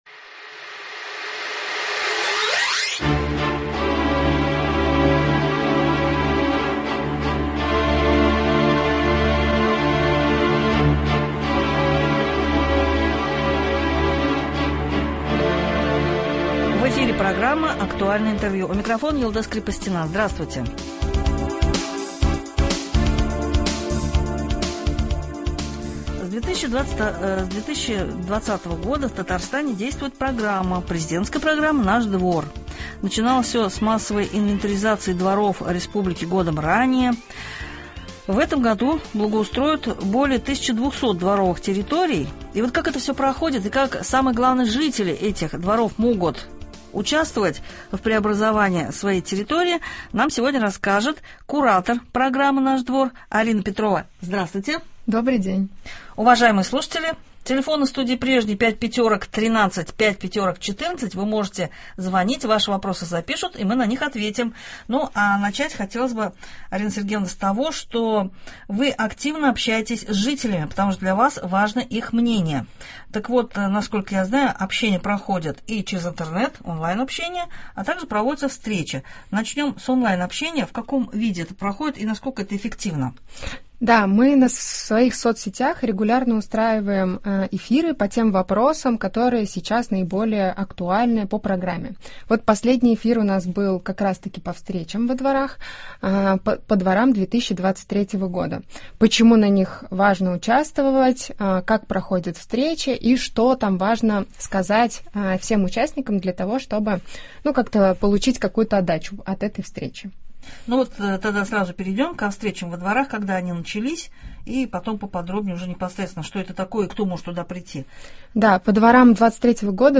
Актуальное интервью (19.10.22)